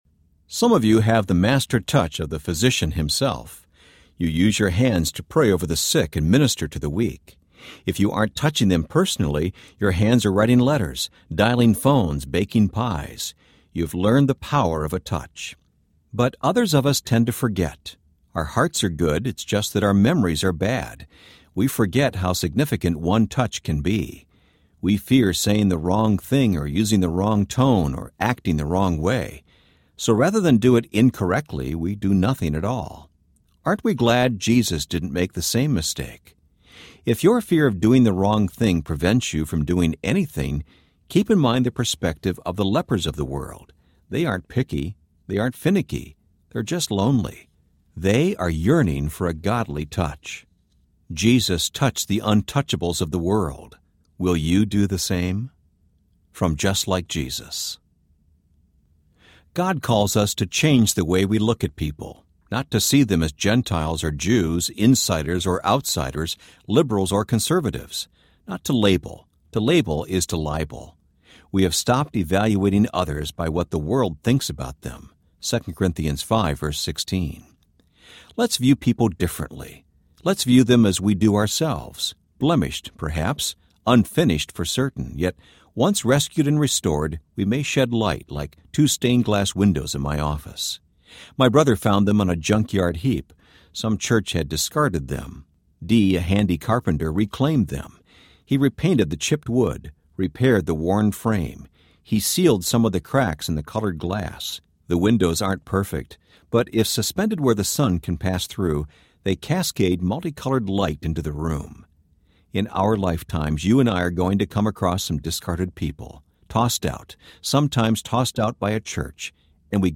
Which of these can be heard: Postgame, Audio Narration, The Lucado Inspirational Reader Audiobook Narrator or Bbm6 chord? The Lucado Inspirational Reader Audiobook Narrator